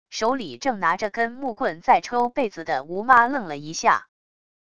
手里正拿着根木棍在抽被子的吴妈愣了一下wav音频生成系统WAV Audio Player